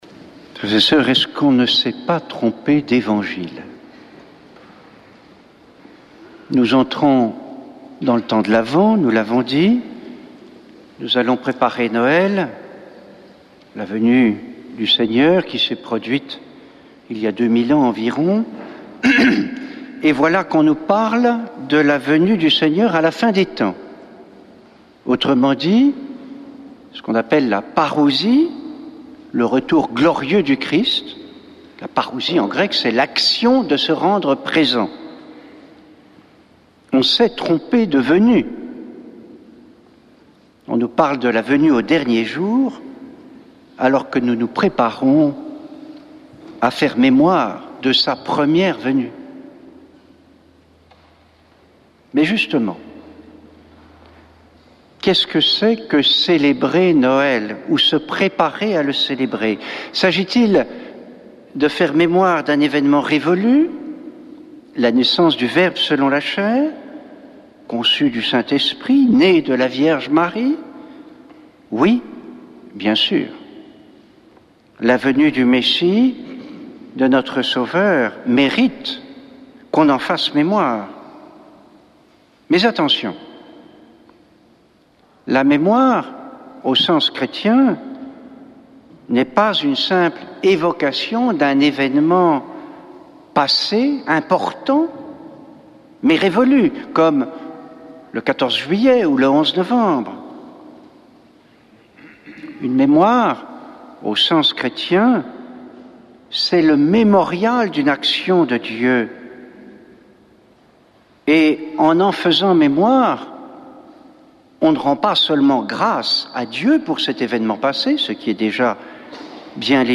Accueil \ Emissions \ Foi \ Prière et Célébration \ Messe depuis le couvent des Dominicains de Toulouse \ Stay woke !